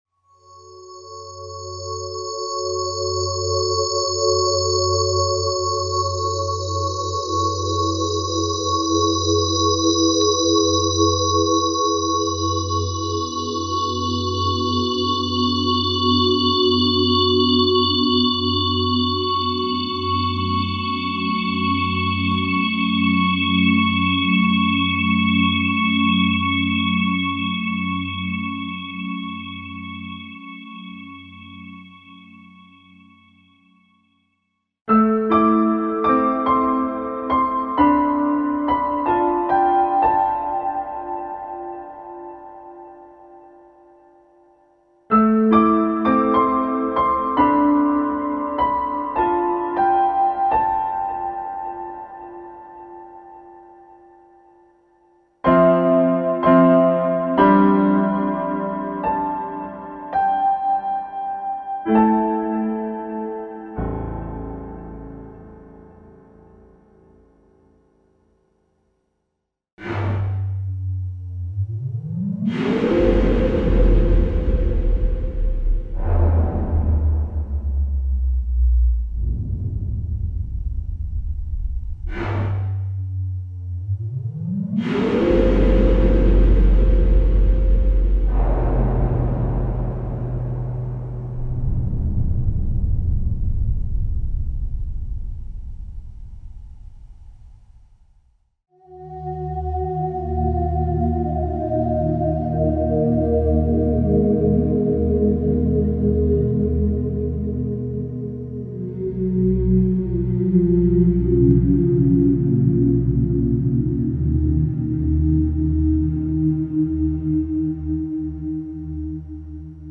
Movie & FX  - original sound programs specially designed for film scoring and soundtrack projects (experimental pads, strings, voices, organs, pianos and synth effects).
Info: All original K:Works sound programs use internal Kurzweil K2500 ROM samples exclusively, there are no external samples used.